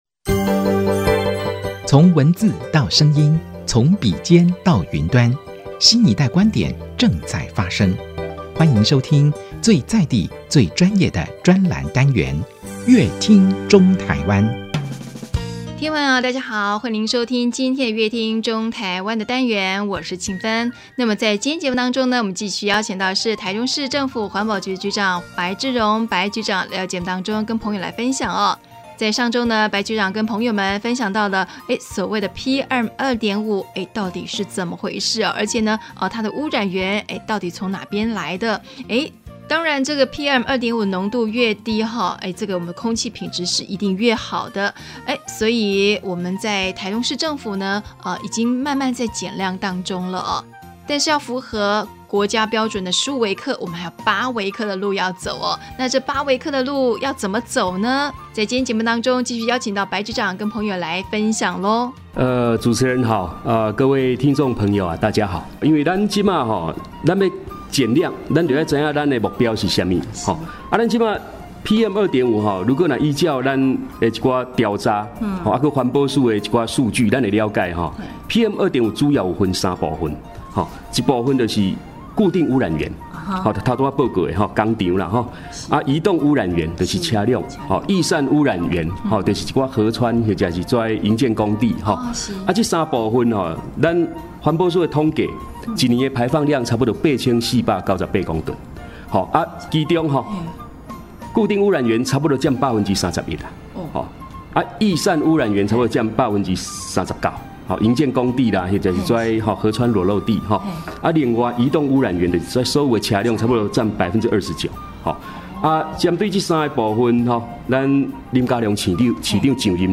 本集來賓：臺中市政府環保局白智榮局長 本集主題：空污減量 台中市汰換補助6都最高 本集內容： 空污指標之一的P